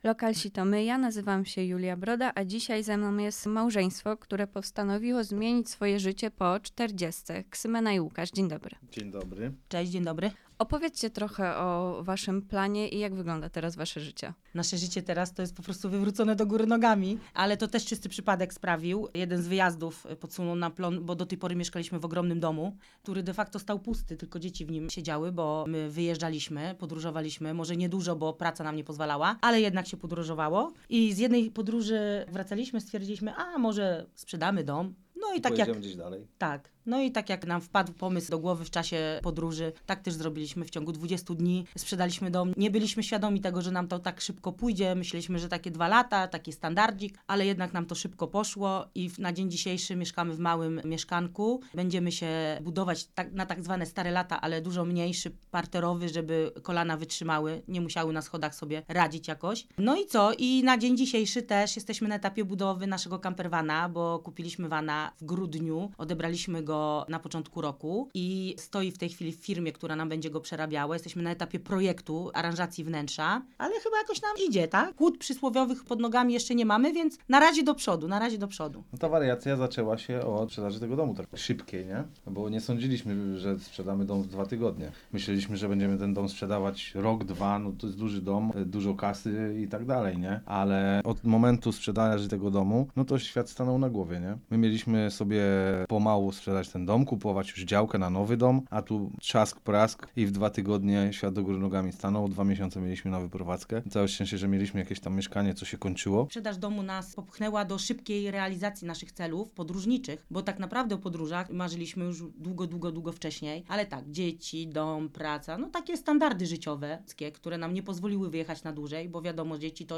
Audycja do odsłuchania poniżej: